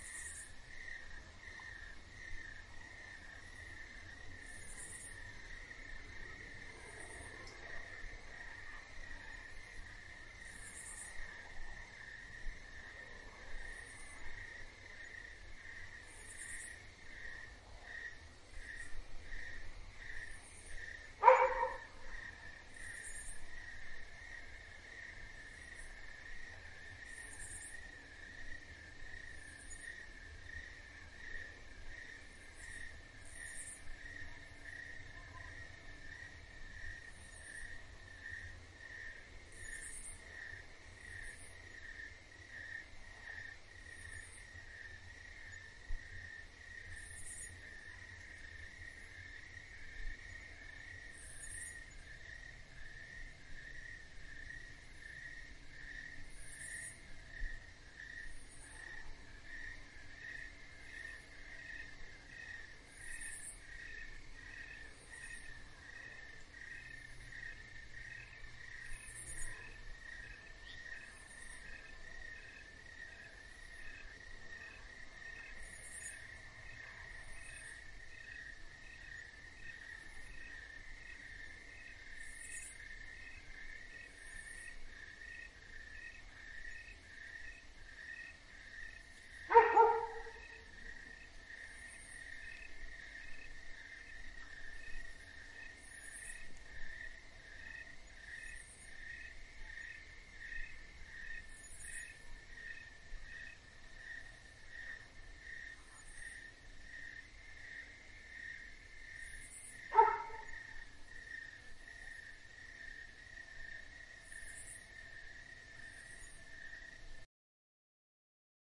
随机 " 蟋蟀的乡村田野之夜，附近有刺耳的蝉鸣和音调+偶尔的狗叫声1
描述：蟋蟀国家田野之夜与附近的蝉蝉和口气+偶尔吠叫dog1.flac
Tag: 蟋蟀 晚上 国家